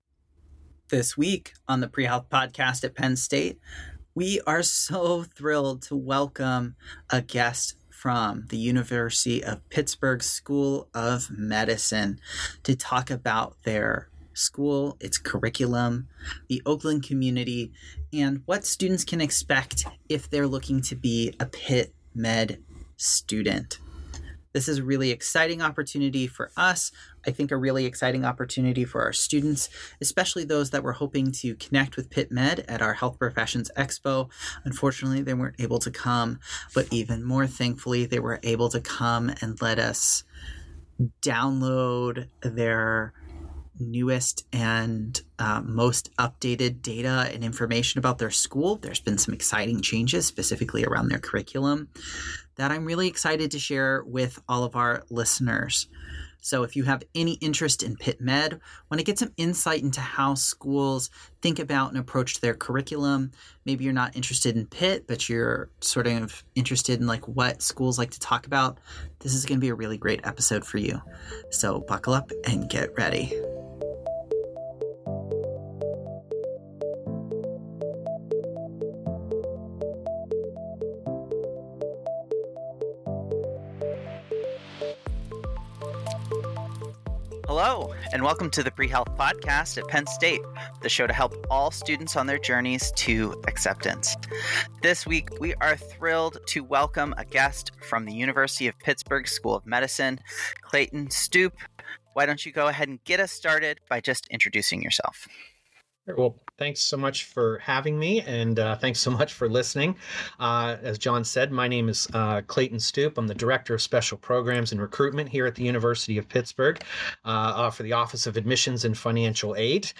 An Interview with Pitt Med